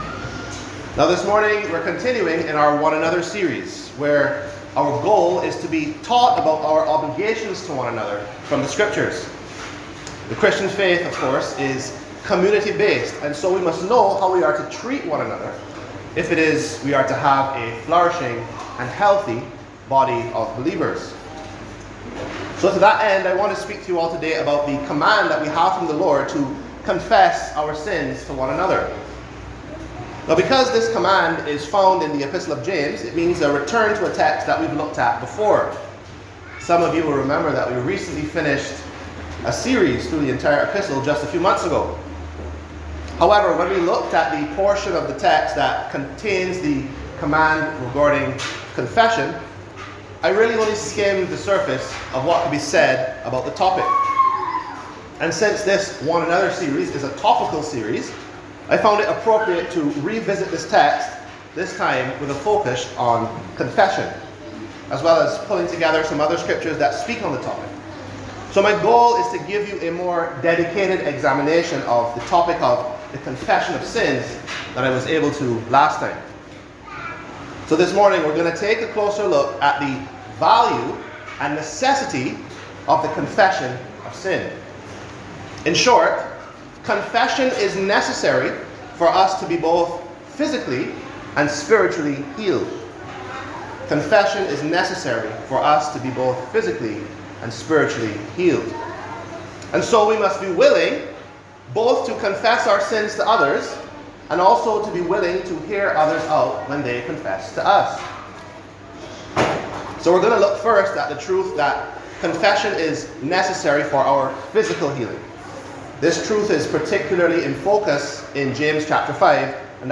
Sermons Podcast - Confess to One Another | Free Listening on Podbean App